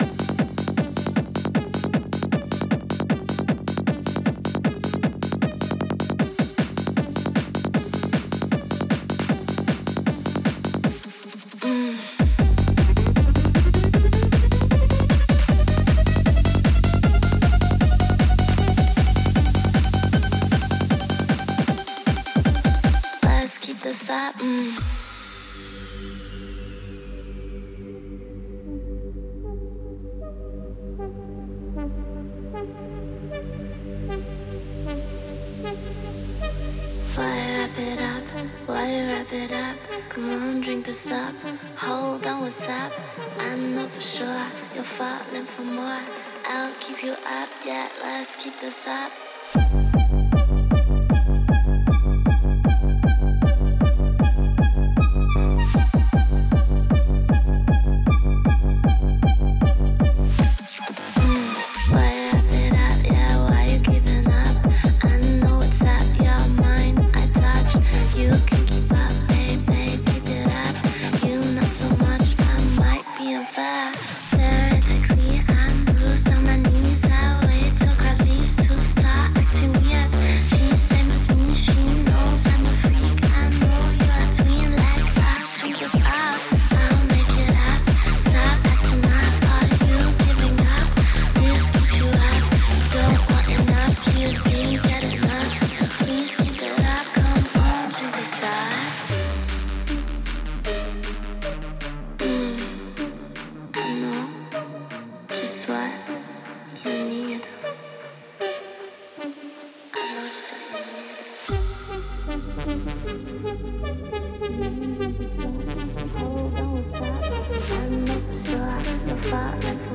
Avant Mastering